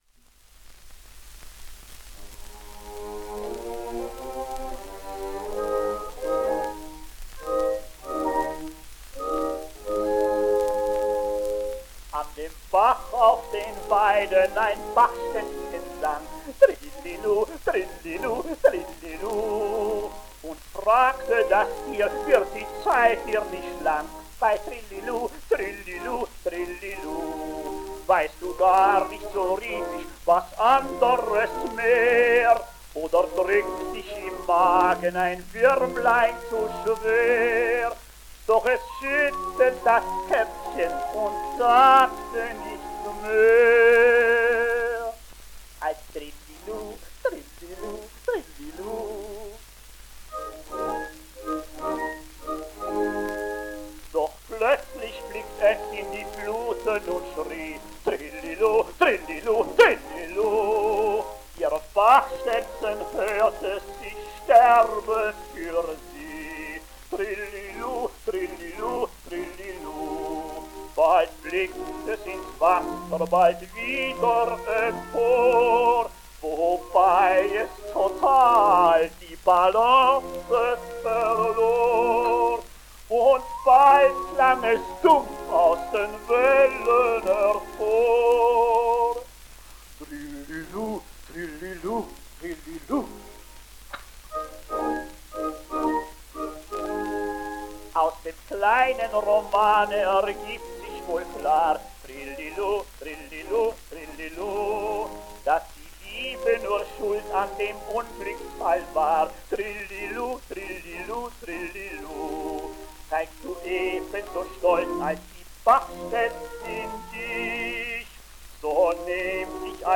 At the Stadttheater Hamburg since 1912, he was the foremost comprimario there, though he occasionally sang main roles like Manrico and Tannhäuser.